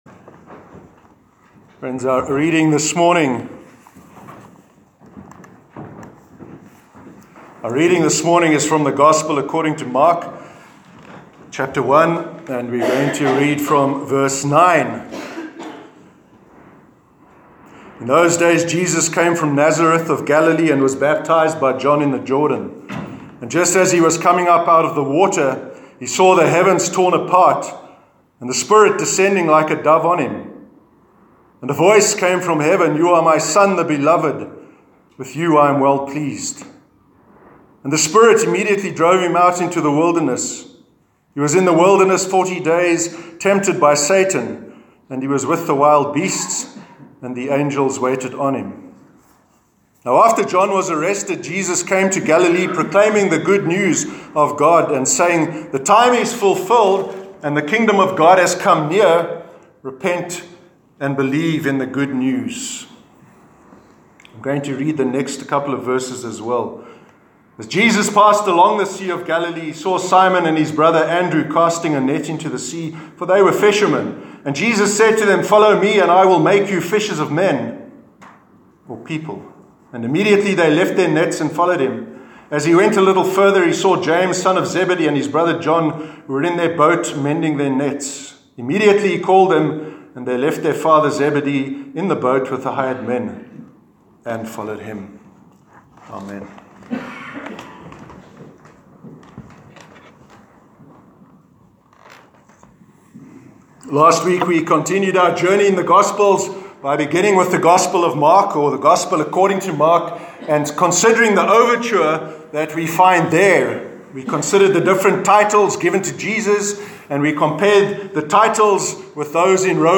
Sermon on the Inauguration of Jesus’s Ministry- 27th January 2019 – NEWHAVEN CHURCH